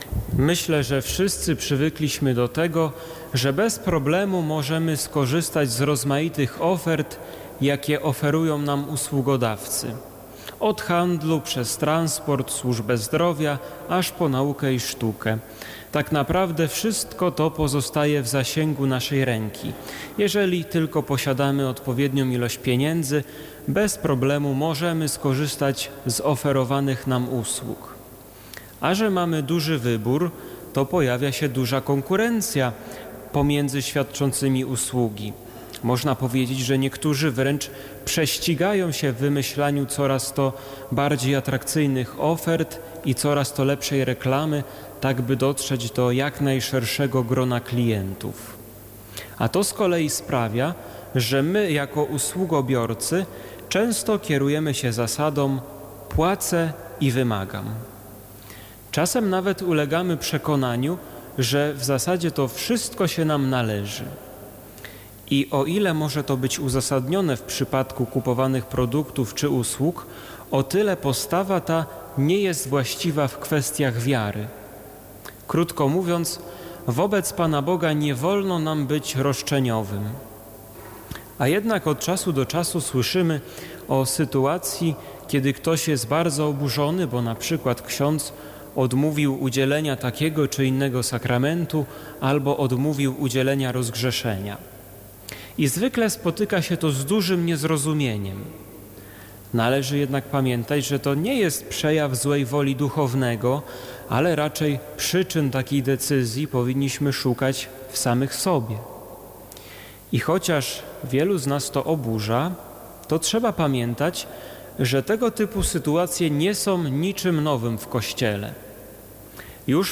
Posłuchaj homilii wygłoszonej podczas Mszy Św. o godz. 10:00 w katedrze wrocławskiej.